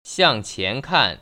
[xiàng qián kàn] 시앙치앤칸